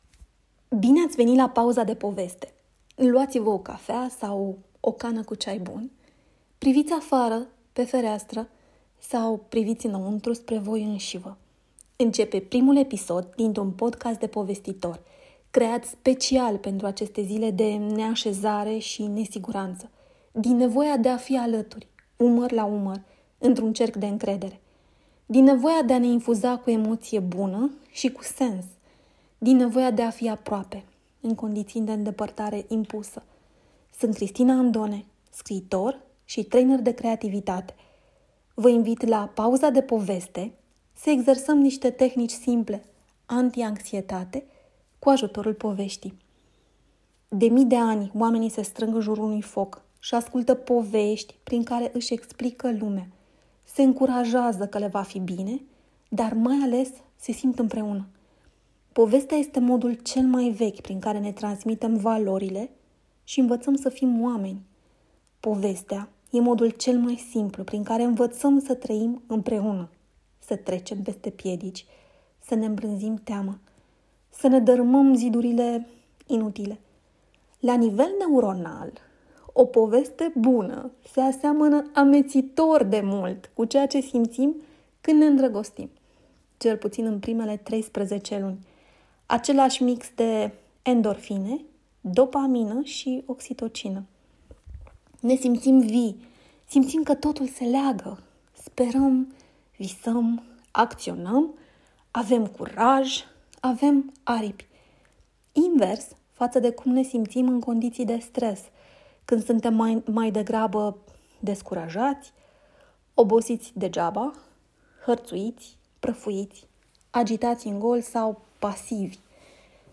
"Pauza de Poveste" este un podcast de povestitor, creat special pentru aceste zile de neașezare și nesiguranță. Din nevoia de a ne infuza cu emoție bună și cu sens.